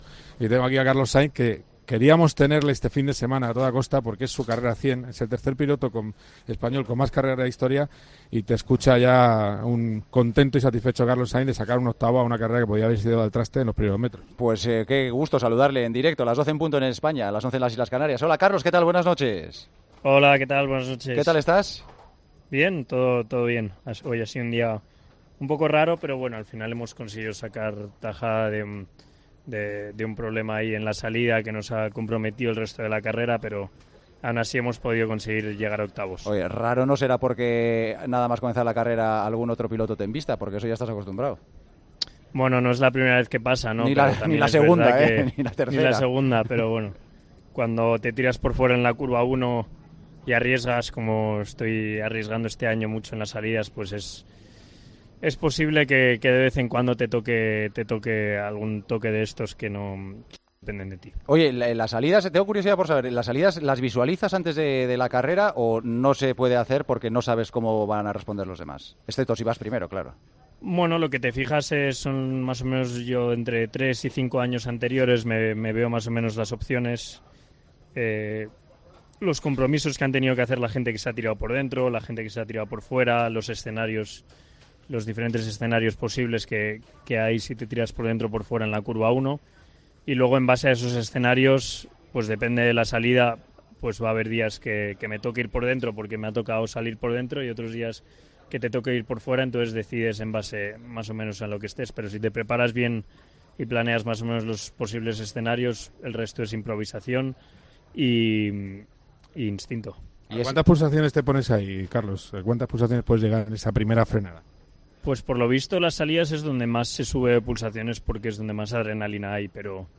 Entrevistamos en Tiempo de Juego a Carlos Sainz después de acabar octavo en el GP de EEUU disputado en Austin, su GP número 100.